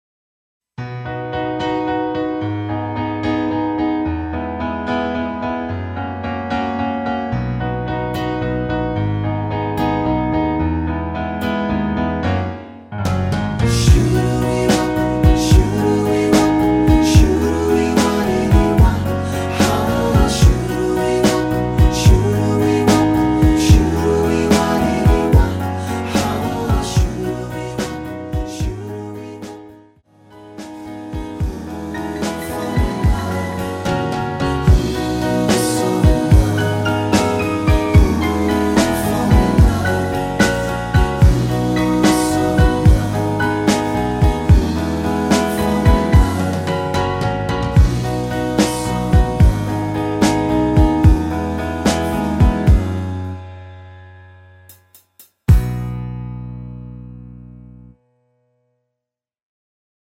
엔딩이 페이드 아웃이라 노래 하시기 좋게 엔딩을 만들어 놓았습니다.(미리듣기 참조)
앞부분30초, 뒷부분30초씩 편집해서 올려 드리고 있습니다.